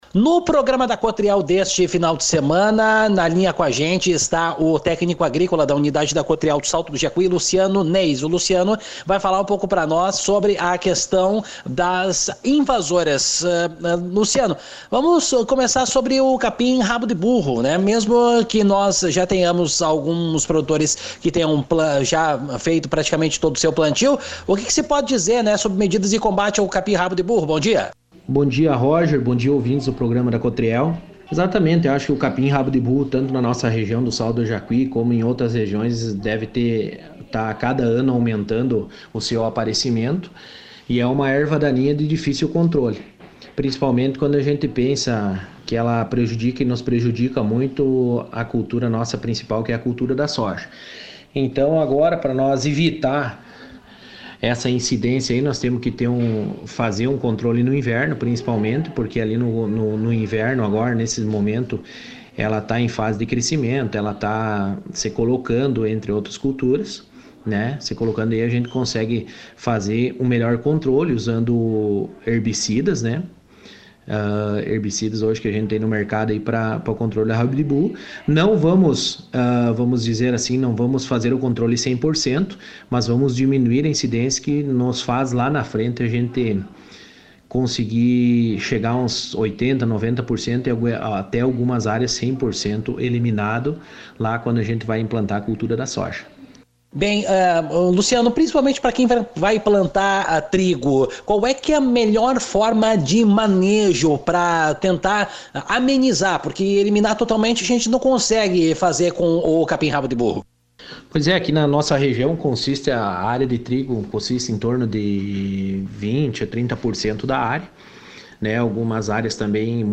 aqui a entrevista.